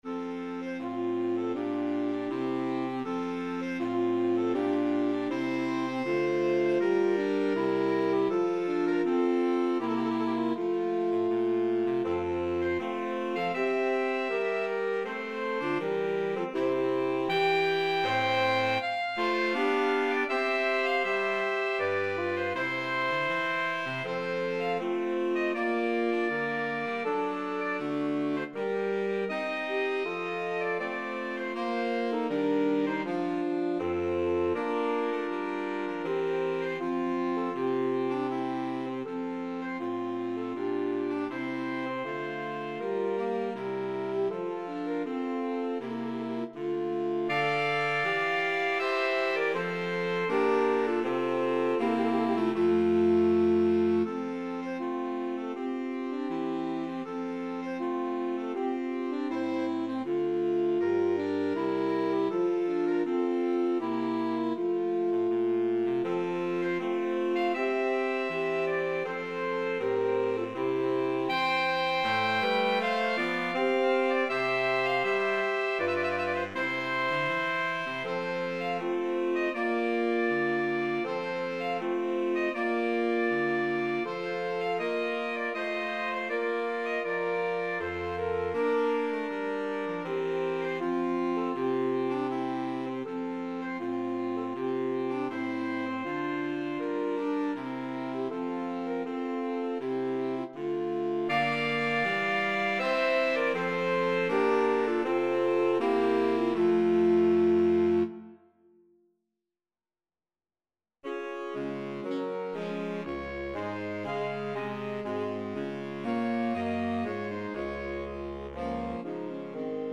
Voicing: Saxophone Quartet (AATB)